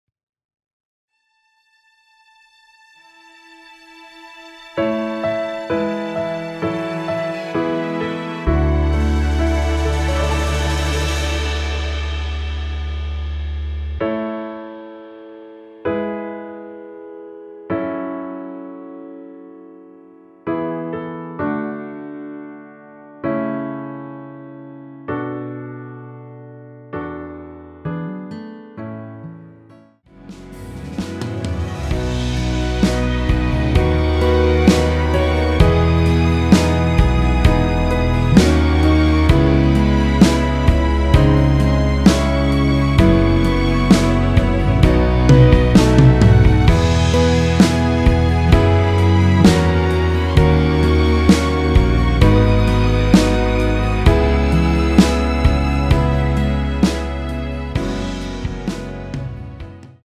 미리듣기는 저작권법상 최고 1분 까지라서
앞부분30초, 뒷부분30초씩 편집해서 올려 드리고 있습니다.
중간에 음이 끈어지고 다시 나오는 이유는
위처럼 미리듣기를 만들어서 그렇습니다.